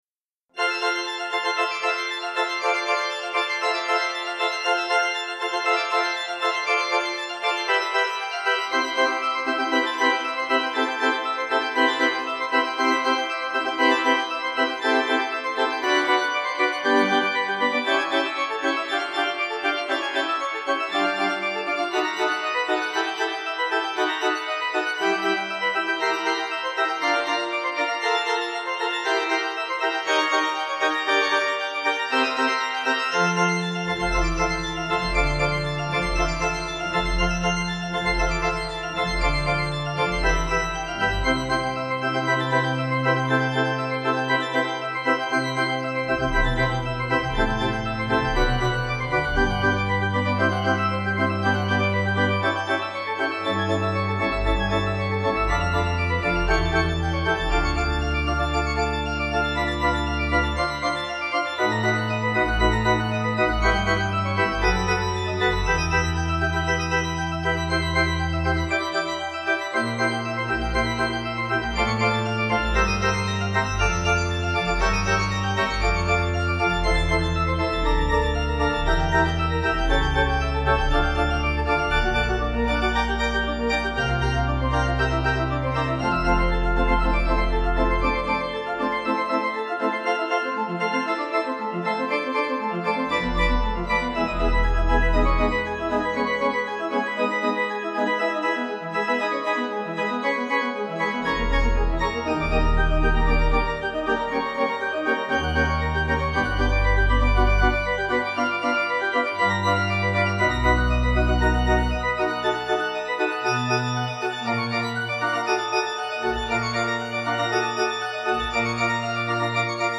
Organ
Easy Listening   F 190.7kb